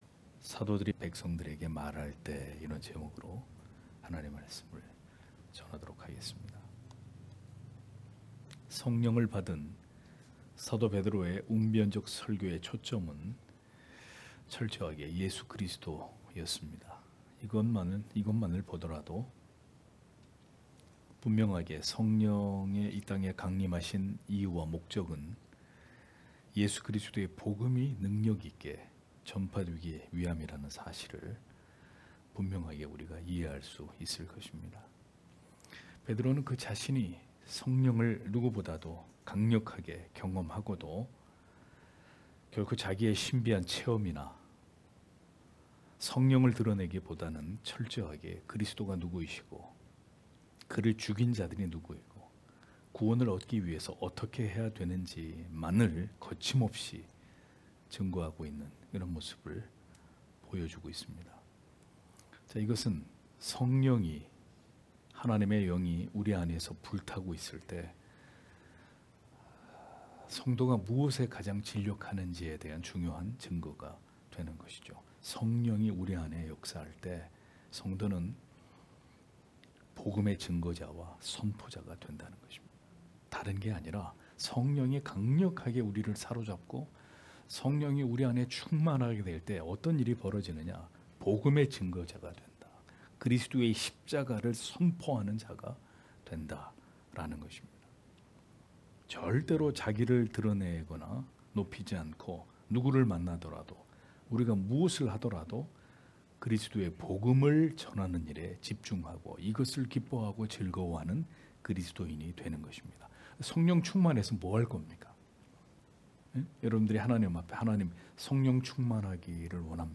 금요기도회 - [사도행전 강해 24] 사도들이 백성들에게 말할 때 (행 4장 1-4절)